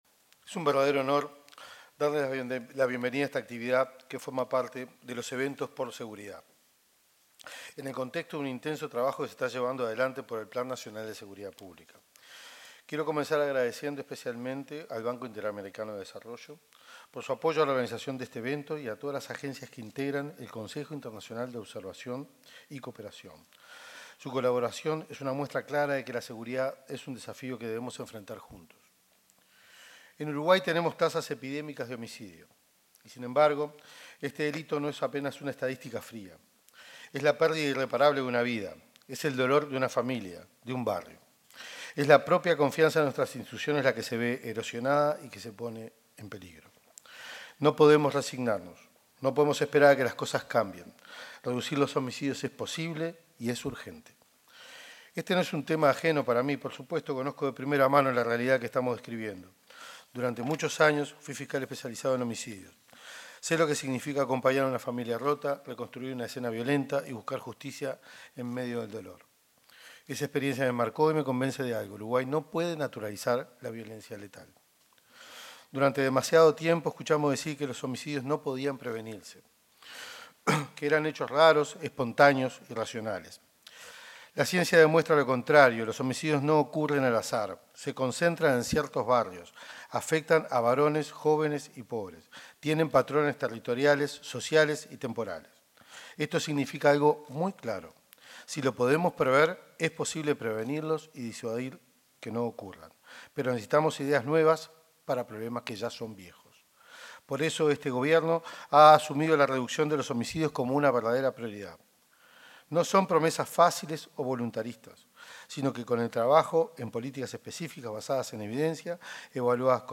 Palabras del ministro del Interior, Carlos Negro
Palabras del ministro del Interior, Carlos Negro 07/10/2025 Compartir Facebook X Copiar enlace WhatsApp LinkedIn En la apertura del evento Construyendo Estrategias para la Reducción de Homicidios, en el marco del Plan Nacional de Seguridad Pública, se expresó el ministro del Interior, Carlos Negro.